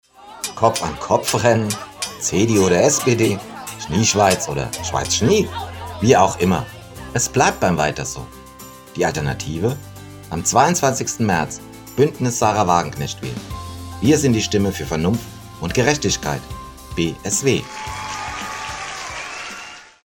Wahlwerbespots Hörfunk